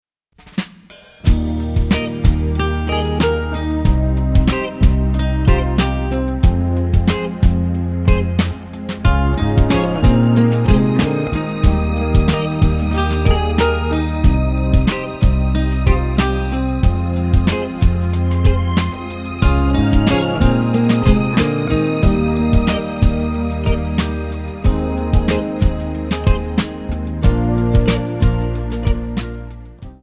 Backing track files: Pop (6706)